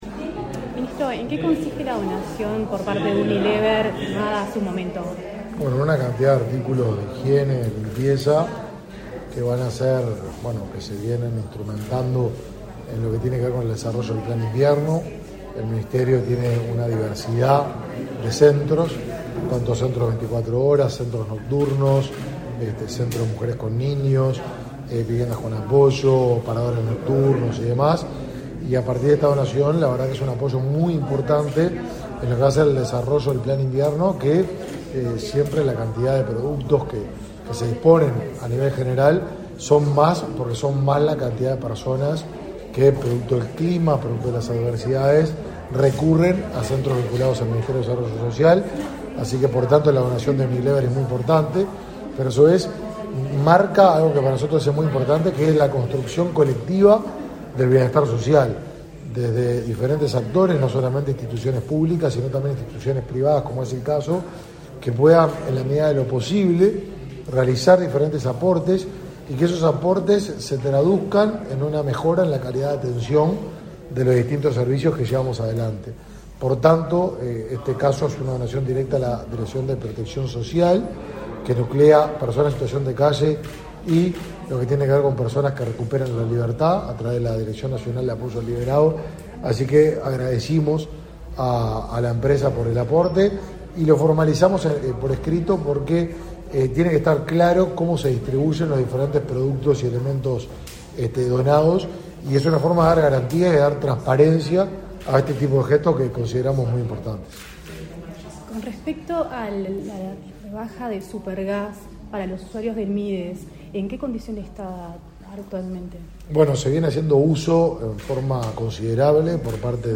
Entrevista al ministro de Desarrollo Social, Martín Lema
Entrevista al ministro de Desarrollo Social, Martín Lema 08/06/2022 Compartir Facebook X Copiar enlace WhatsApp LinkedIn Este 8 de junio, el Ministerio de Desarrollo Social firmó convenio con una empresa por la donación de productos para programas que asisten a personas en situación de calle. Tras el evento, el ministro Martín Lema efectuó declaraciones a Comunicación Presidencial.